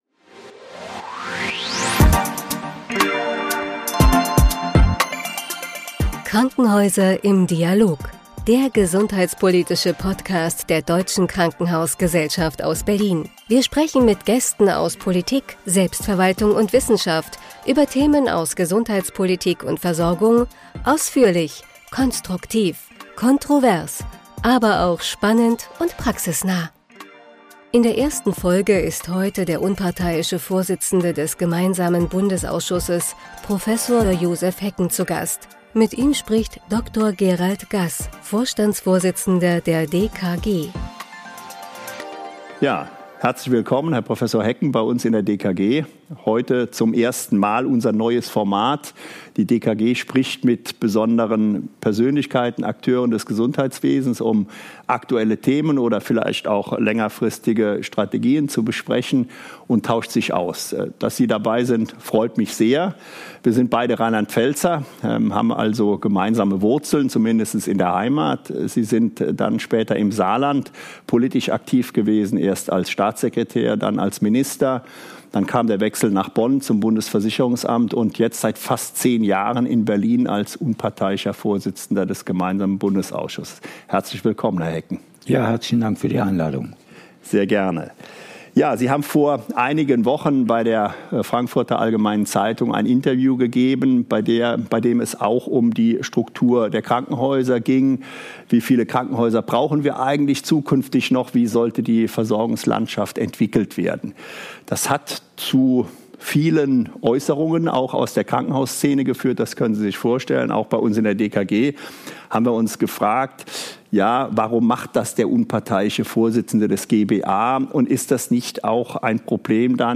In der 1. Folge ist Prof. Josef Hecken, unparteiischer Vorsitzender des Gemeinsamen Bundesausschusses (G-BA), zu Gast.